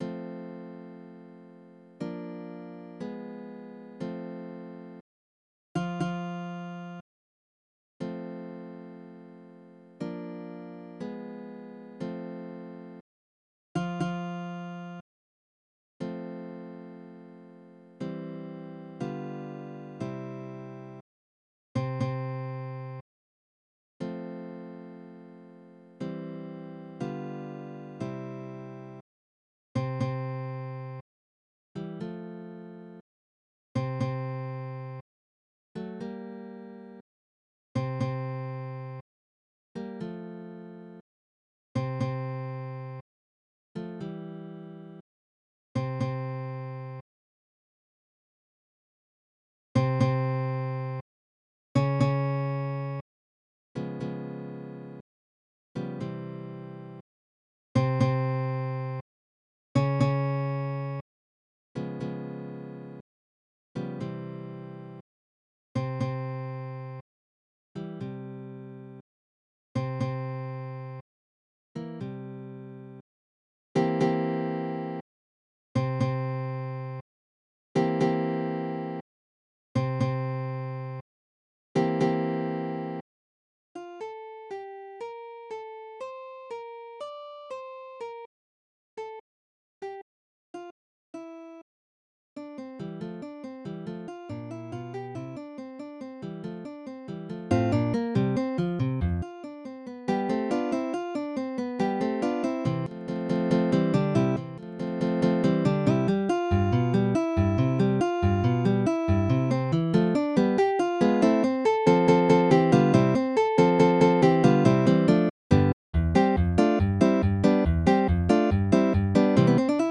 クラシックギター独奏曲楽譜一覧